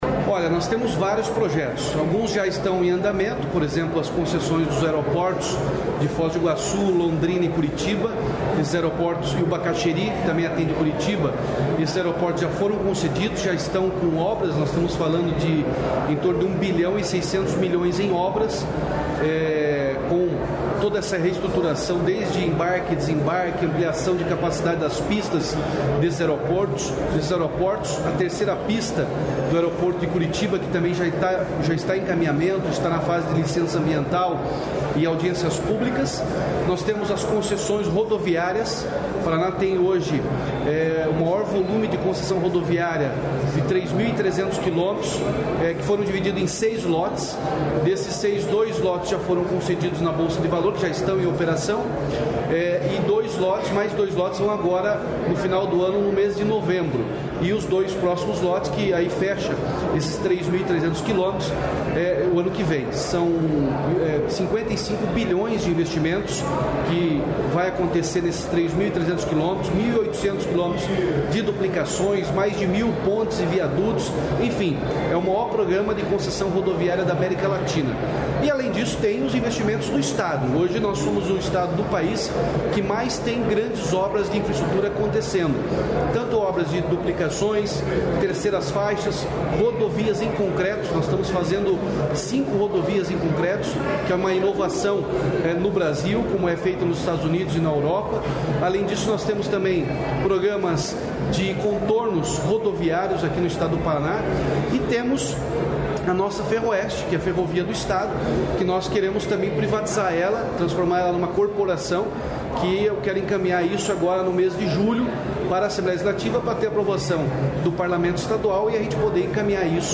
Sonora do governador Ratinho Junior sobre projetos em infraestrutura e ações para prevenção a eventos climáticos